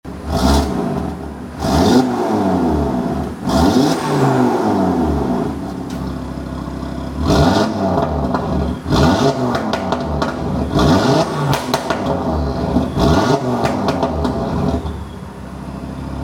Revs_VBC.mp3